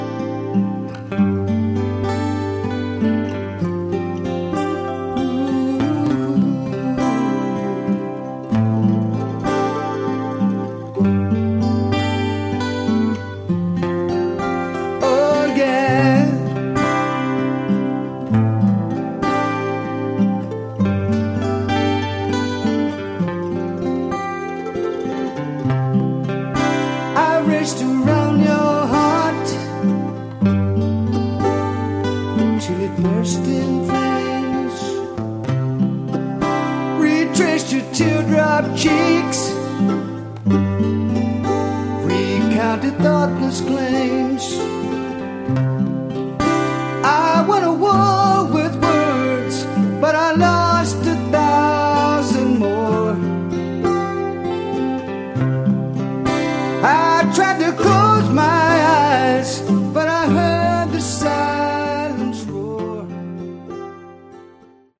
Category: AOR
guitar, bass, keyboards
drums